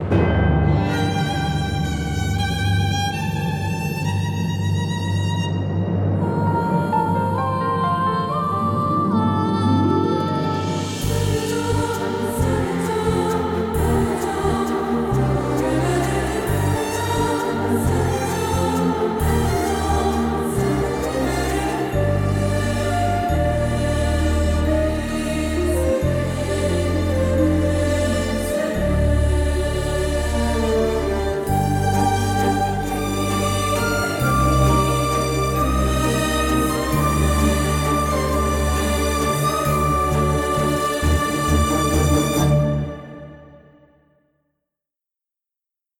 without disturbing sounds and dialogues.
background score